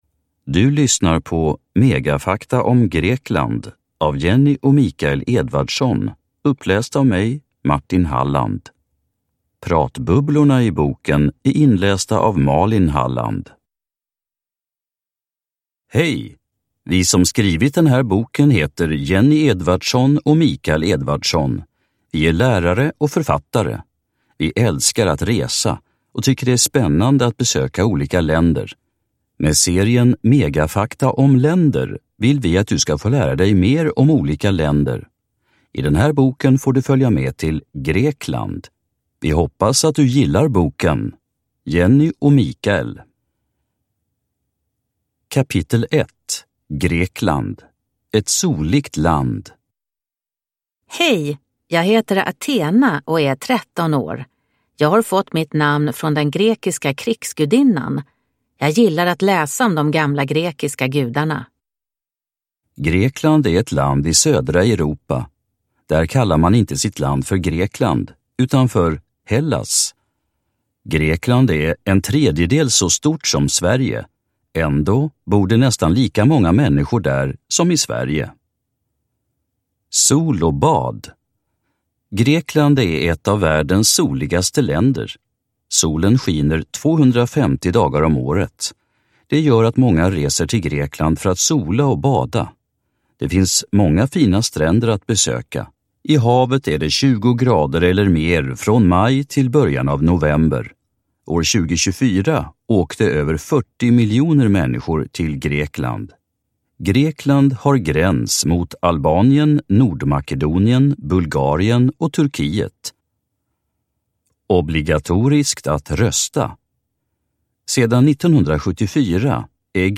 Megafakta om länder. Grekland – Ljudbok